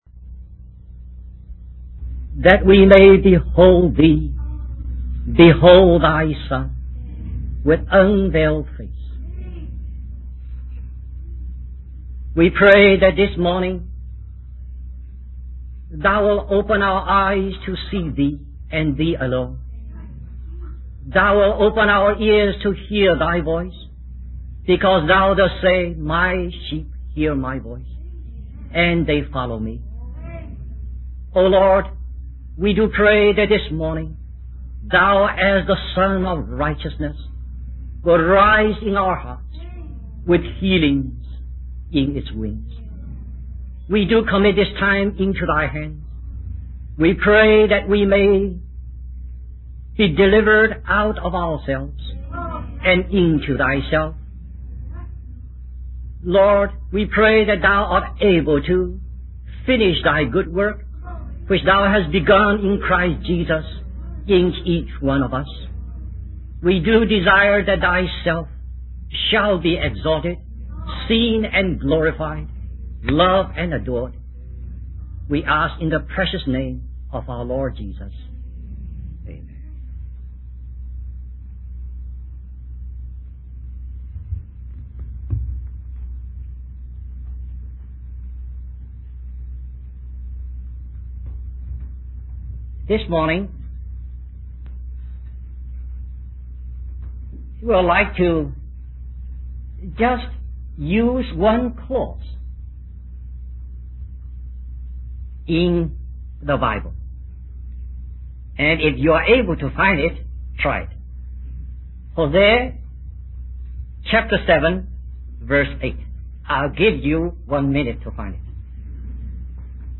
In this sermon, the speaker emphasizes the importance of maintaining a balance between life and knowledge. He warns against being extreme in either direction, as it can lead to negative consequences.